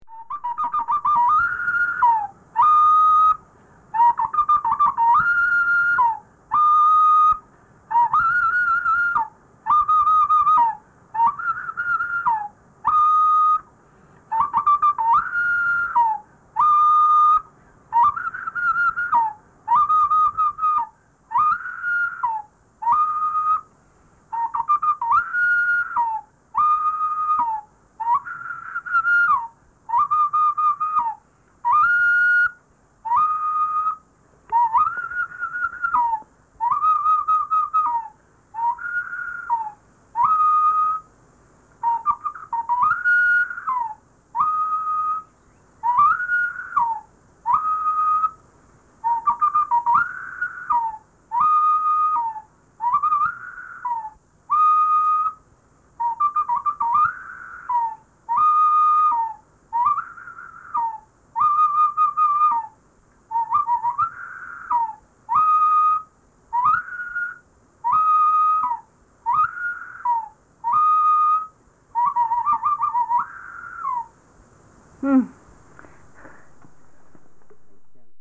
U'wa whistling
U'wa whistling from the Andes in north-east Colombia.